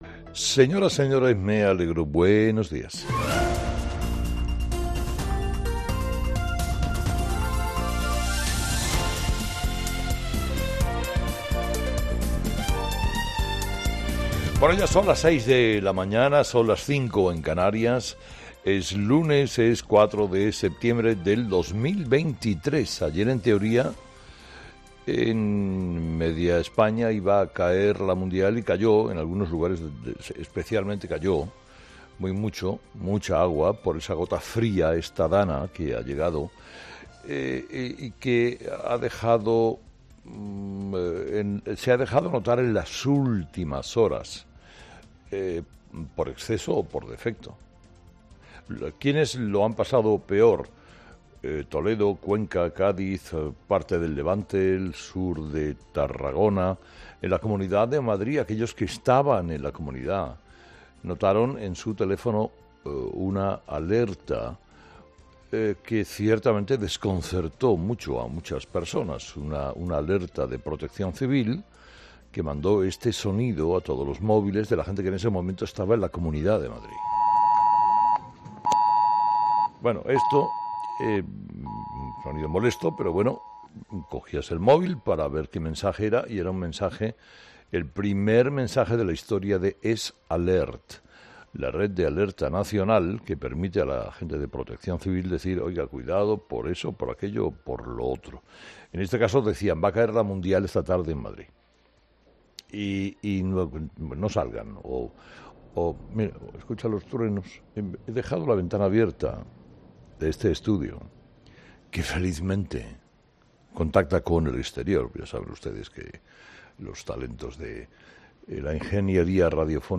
Escucha el análisis de Carlos Herrera a las 06:00 h. en Herrera en COPE el lunes 4 de septiembre
Carlos Herrera, director y presentador de 'Herrera en COPE', comienza el programa de este viernes analizando las principales claves de la jornada que pasan, entre otras cosas, por la semana clave para la investidura de Feijóo o Sánchez.